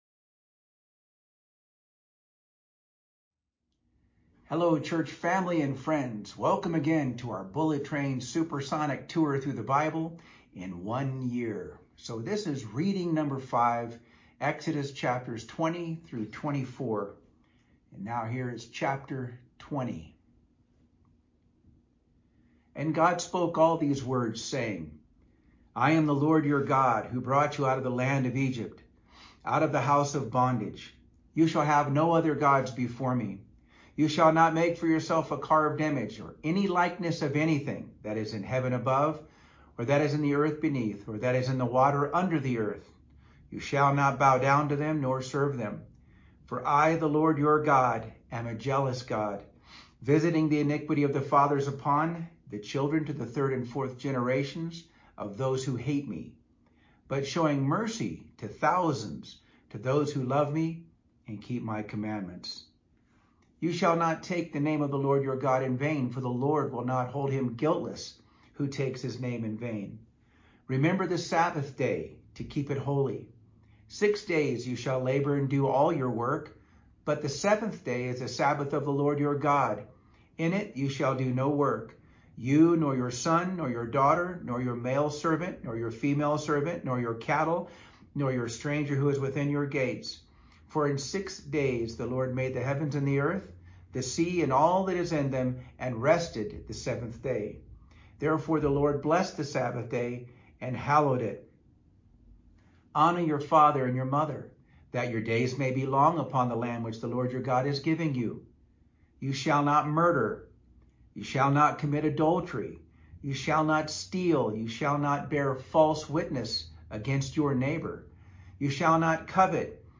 Tour-Through-The-Bible-Reading-5-Exodus-20-24-CD.mp3